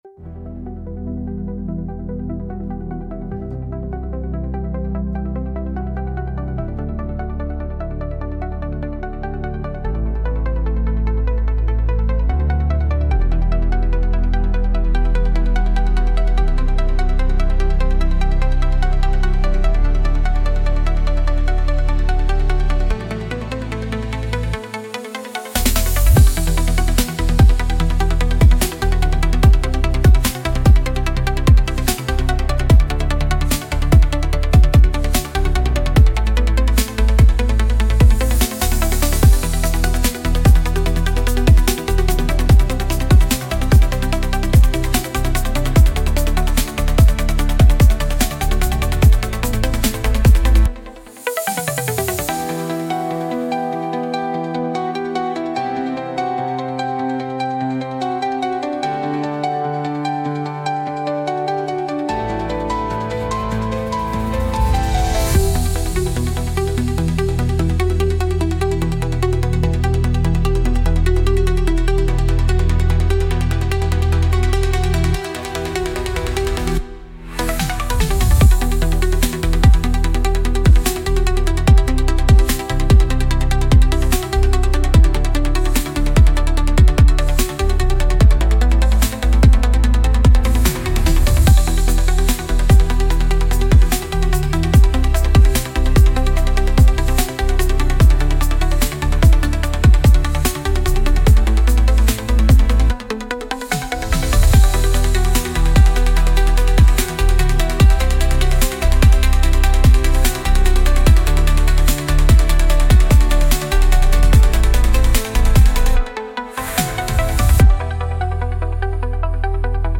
Instrumental - Heartbeat Mirage”